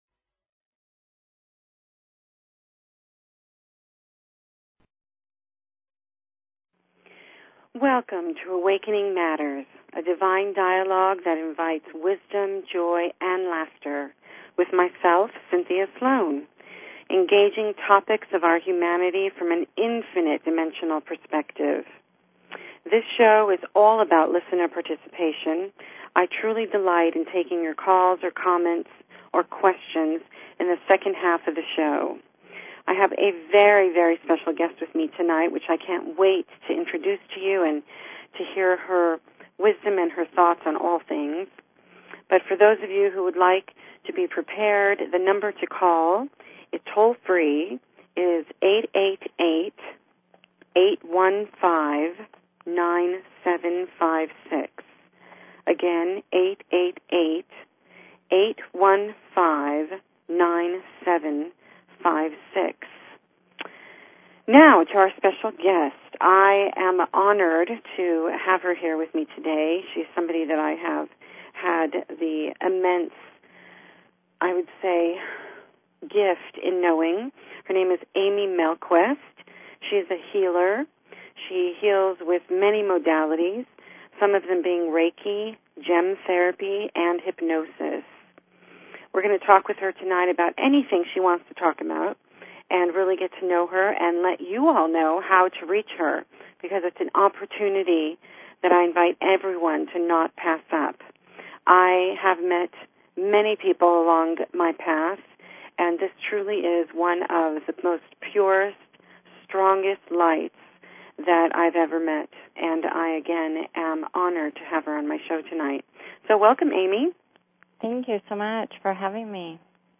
Talk Show Episode, Audio Podcast, Awakening_Matters and Courtesy of BBS Radio on , show guests , about , categorized as
A spiritual dialogue that invites divine wisdom, joy and laughter. Engaging topics of our humanity from an infinite dimensional perspective.